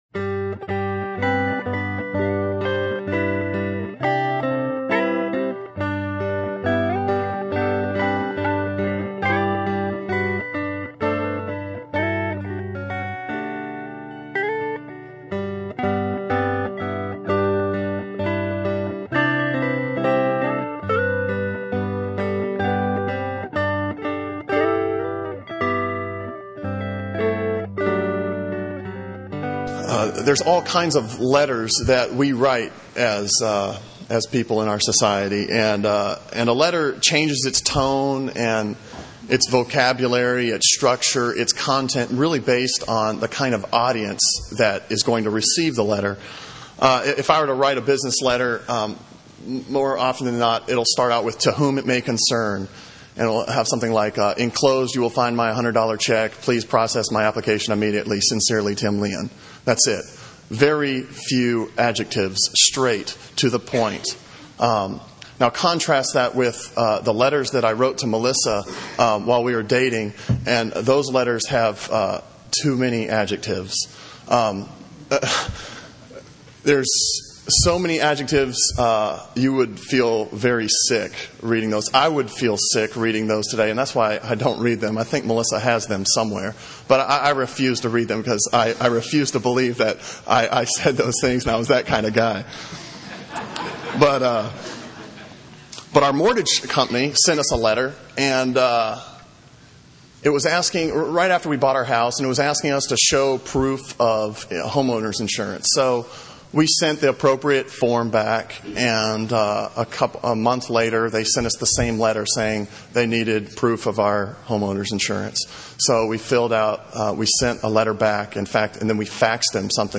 Sermon on Philippians 1:12-18a from September 17